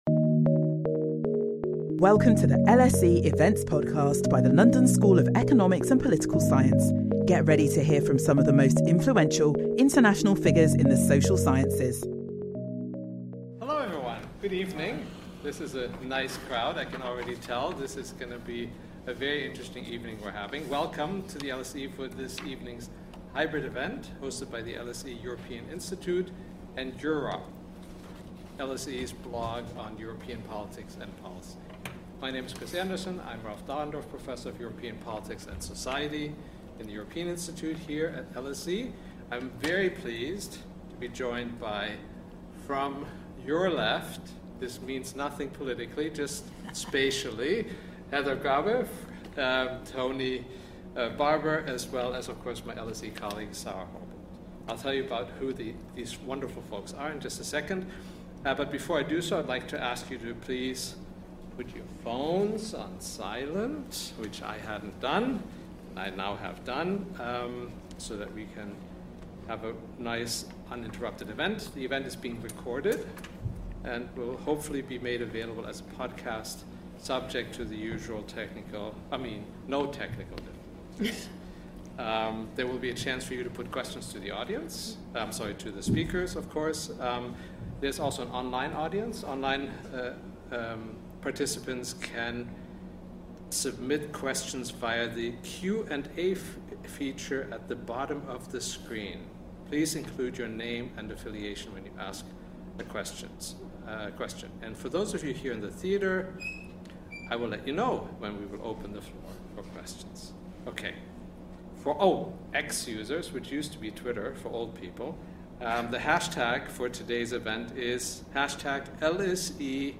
On the first day of voting in the elections, our panel discuss Eurosceptic parties, climate change, migration and the wars in Gaza and Ukraine and more.